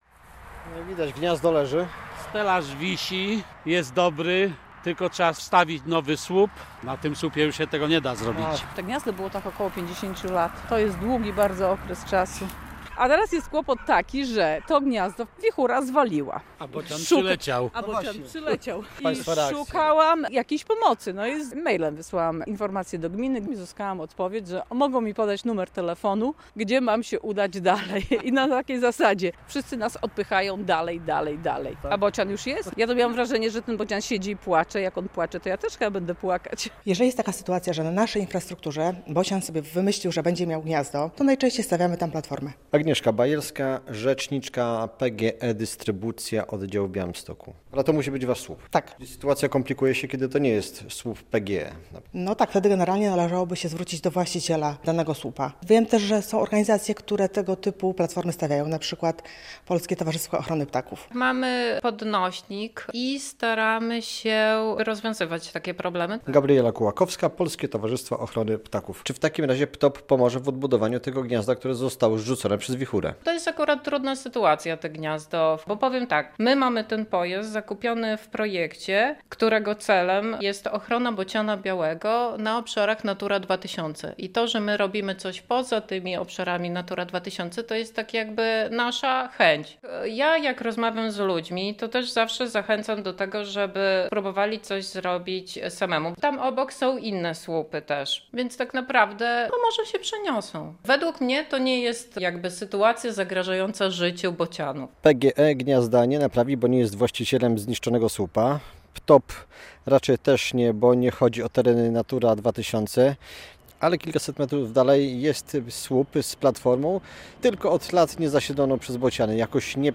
W podbiałostockich Protasach pojawił się bocian - próbuje odbudować swój dom, który zniszczyła wichura - relacja